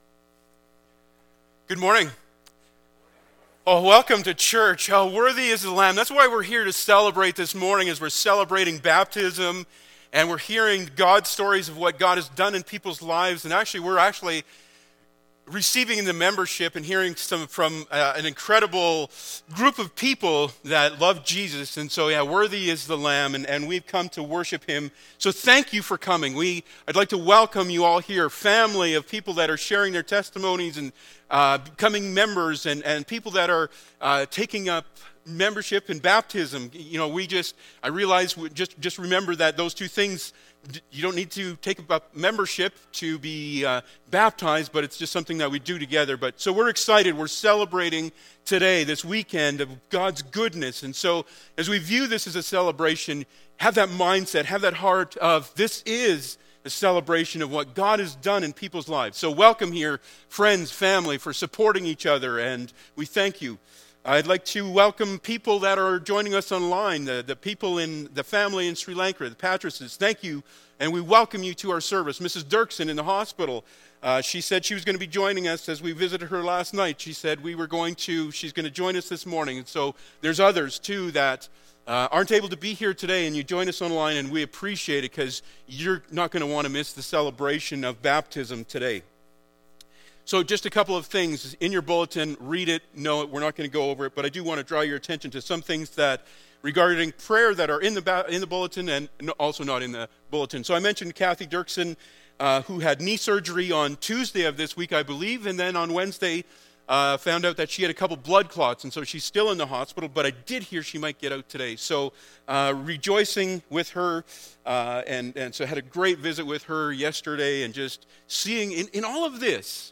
Passage: Matthew 28:19 Service Type: Sunday Morning Topics